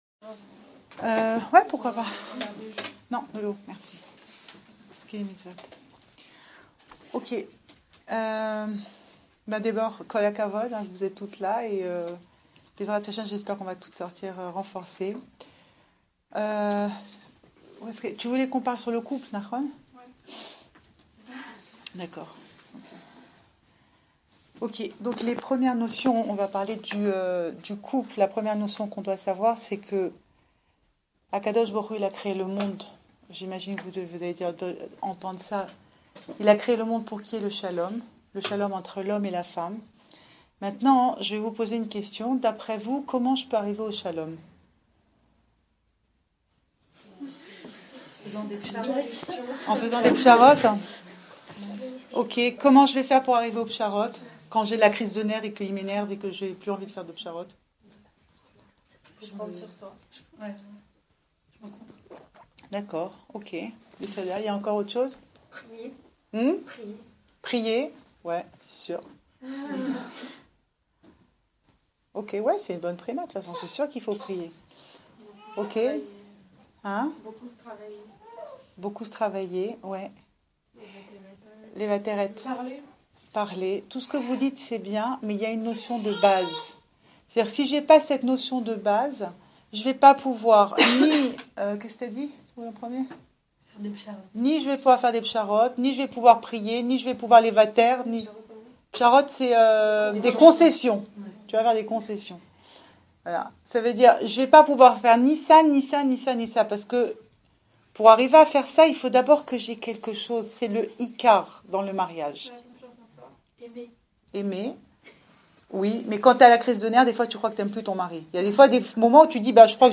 Cours audio
Enregistré à Kiriat Yovel